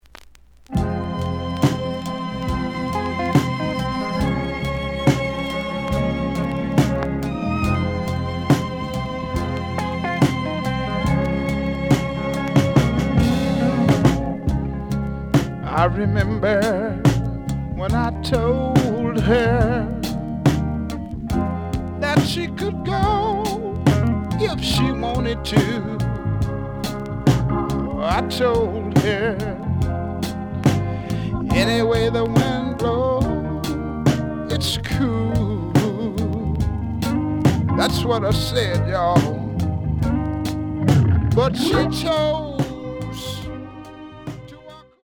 The audio sample is recorded from the actual item.
●Genre: Soul, 70's Soul
B side plays good.)